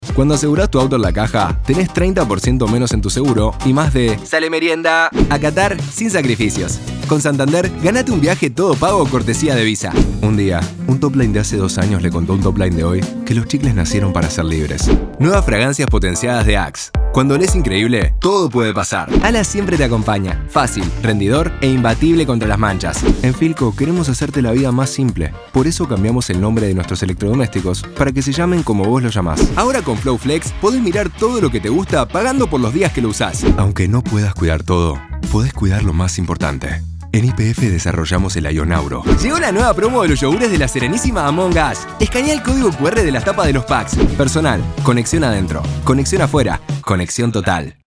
Argentinian Spanish
Young Adult
Commercial